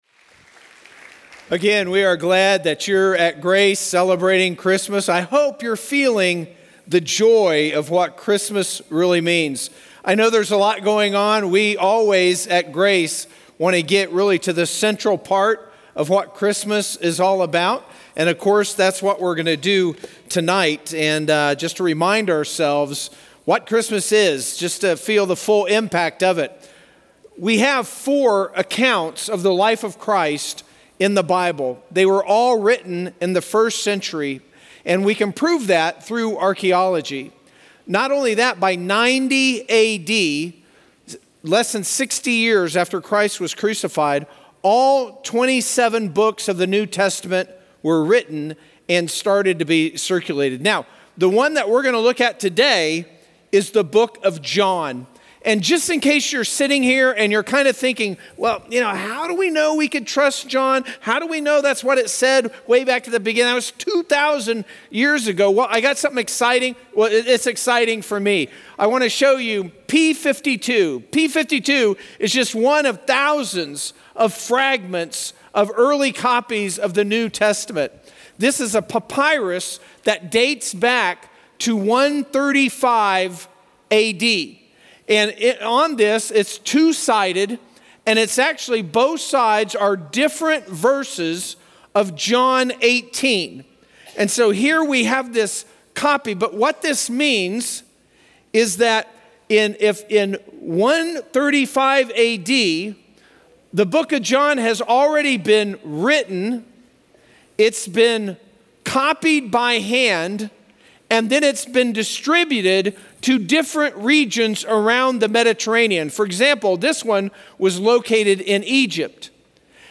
ChristmasatGrace3|FremontChristmasEve.mp3